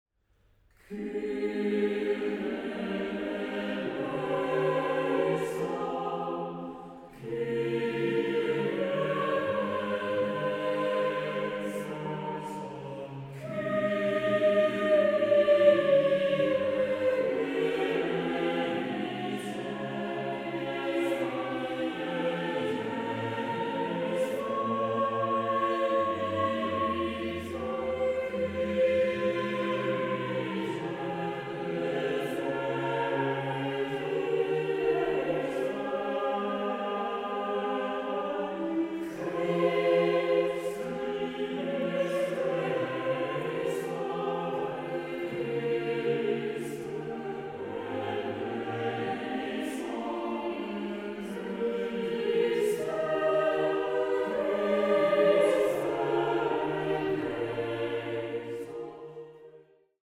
Chamber choir